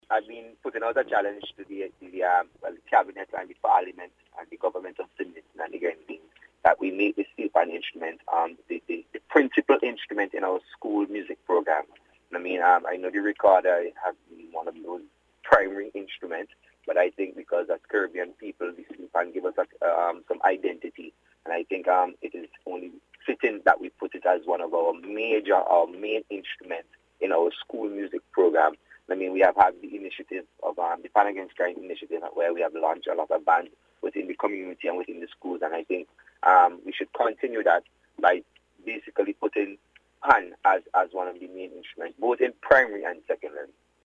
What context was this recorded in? made the call during a recent interview with NBC News.